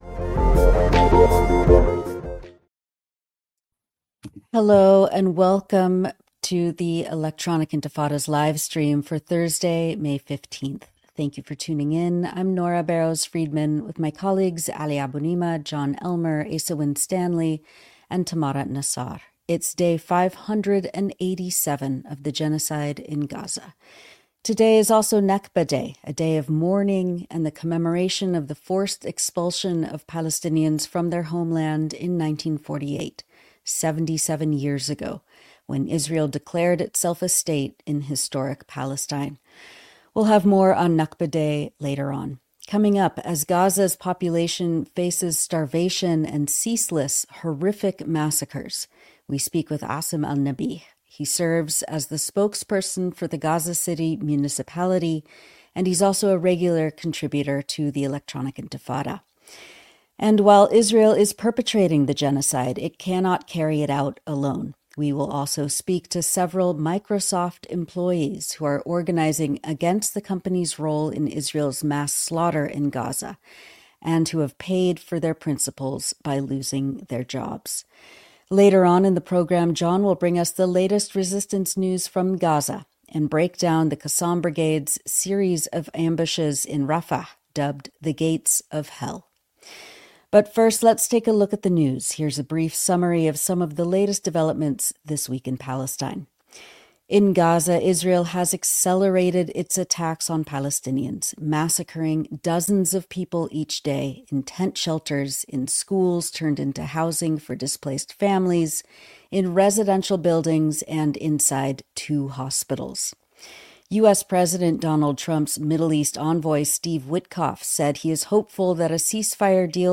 Livestream: Big tech powers genocide but Microsoft workers organize